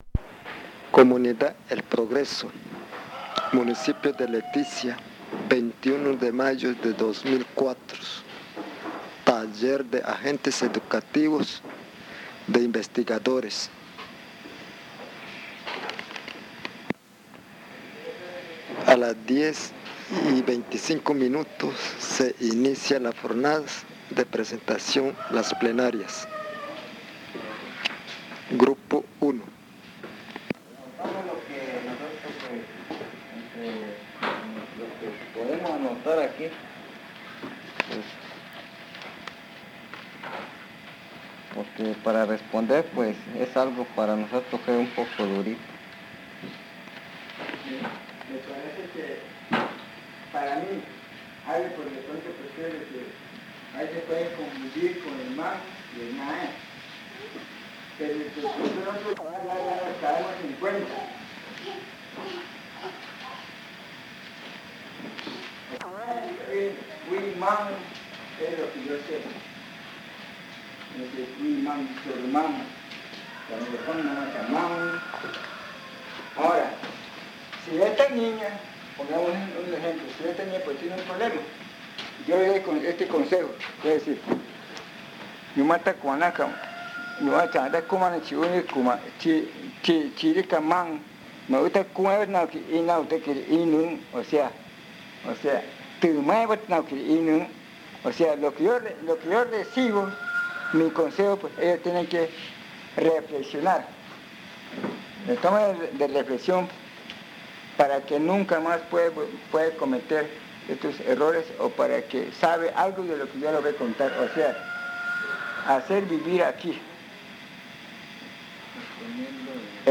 El audio contiene los lados A y B del casete.